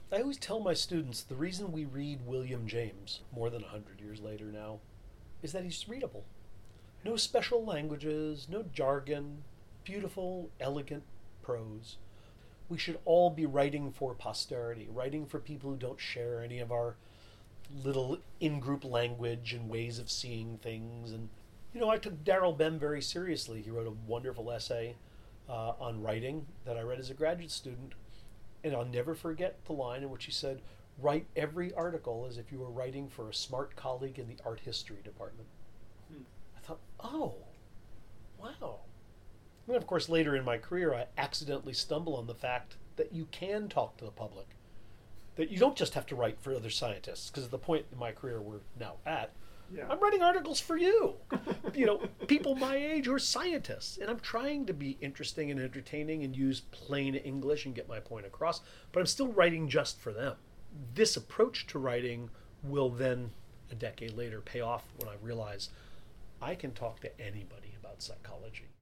We spent some time discussing writing and how his writing developed.  Here’s one part of his description, in which he addresses his approach to writing: